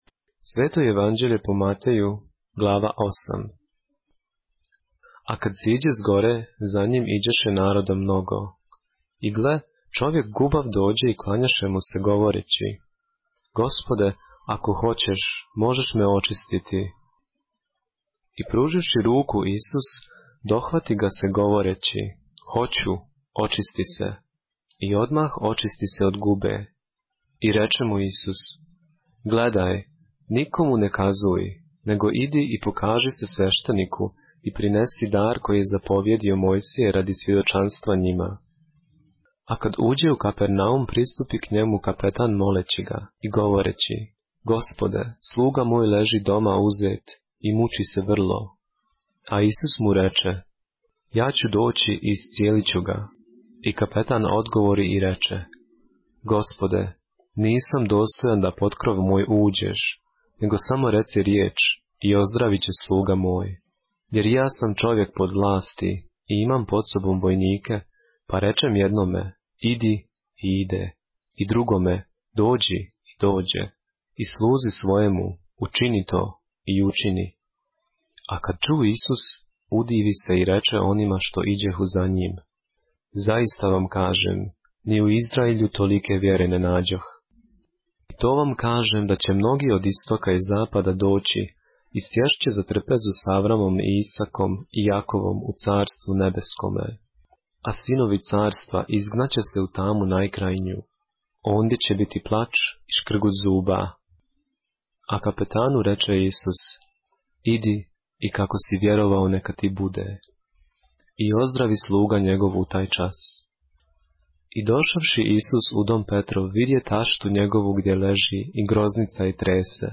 поглавље српске Библије - са аудио нарације - Matthew, chapter 8 of the Holy Bible in the Serbian language